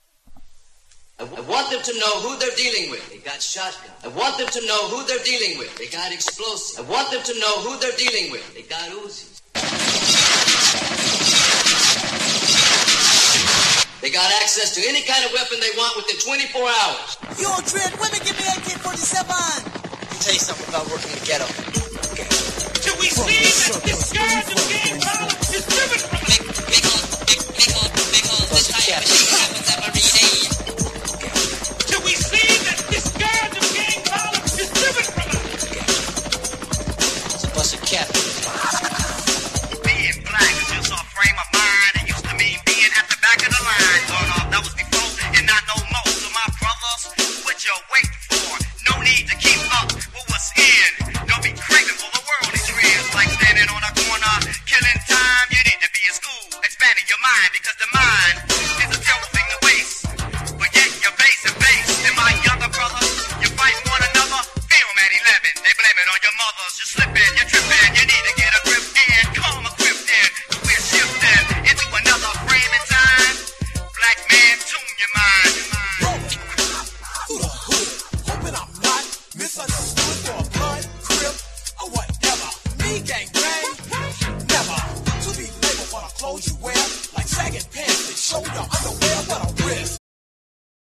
CALIFORNIA産マイナーミドル！！